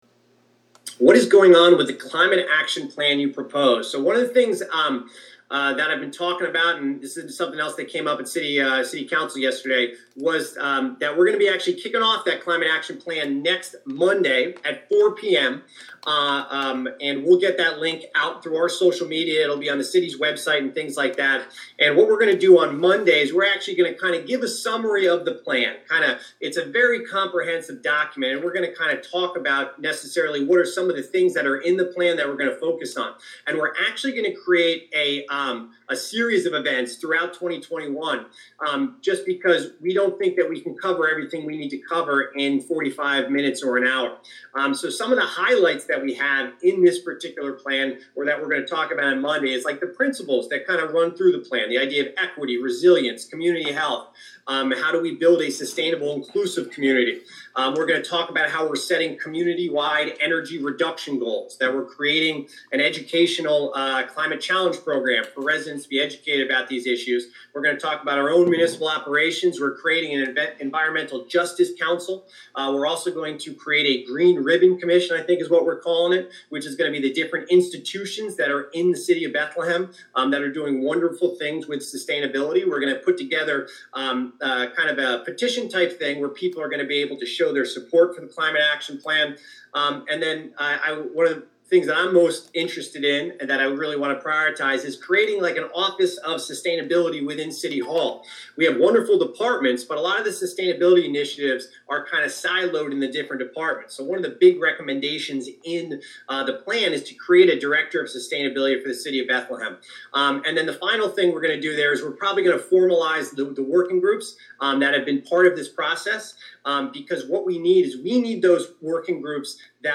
Reynolds Virtual Town Hall April 7